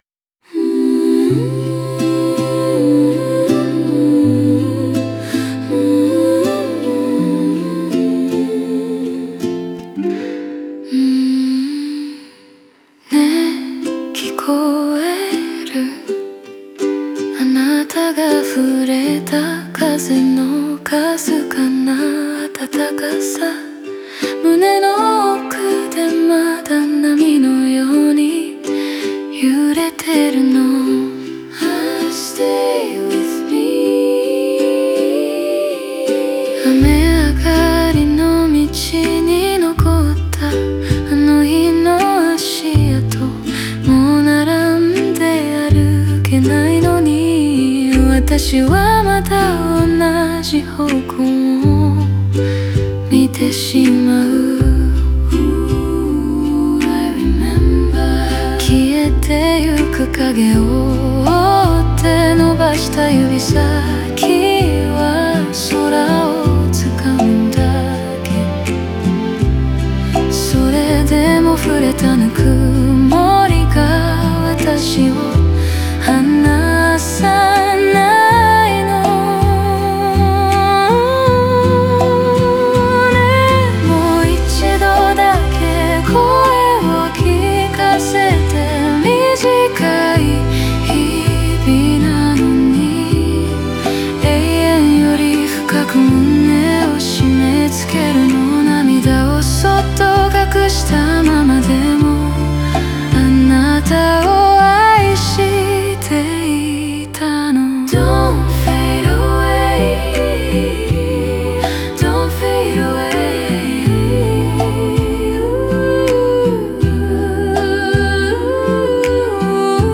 オリジナル曲♪
全体として、柔らかくも深い感情の流れを持つ、語りかける女性ボーカル向けのバラードである。